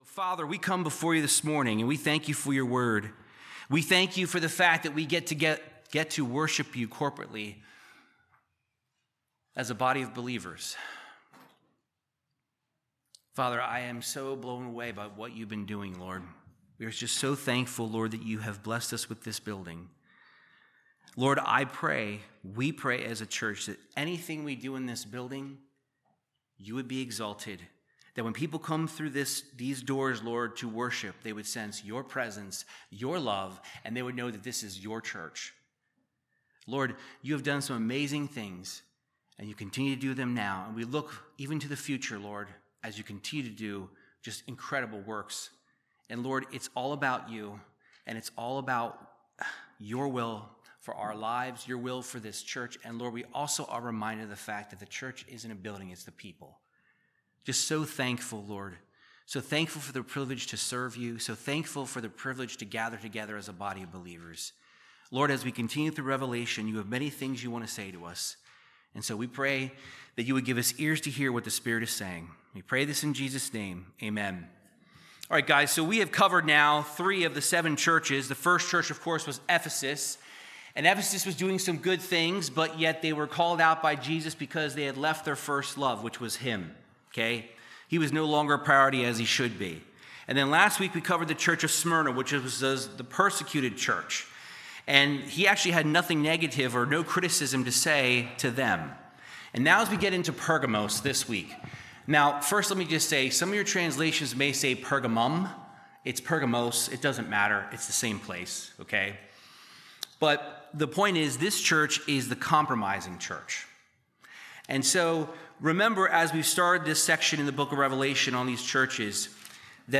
Verse by verse Bible teaching through the book of Revelation chapter two verses 12-17